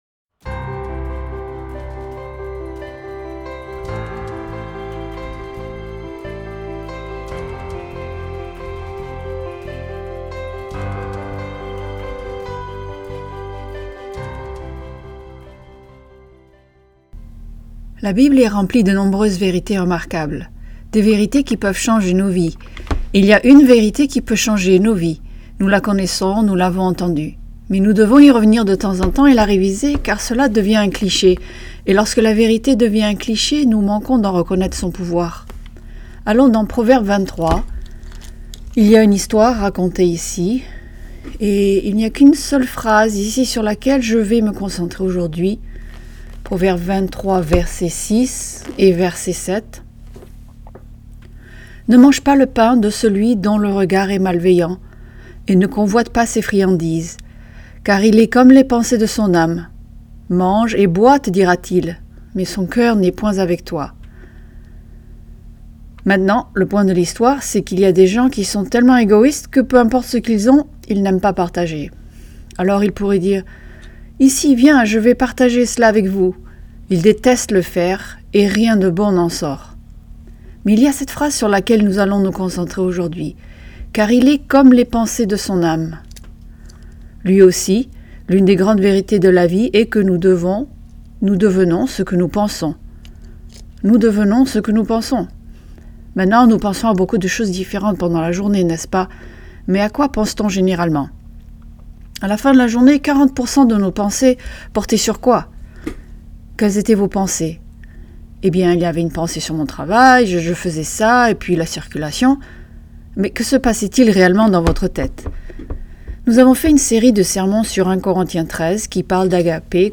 Ce sermon discute et explique le fait que l'amour ne se souvient pas du mal.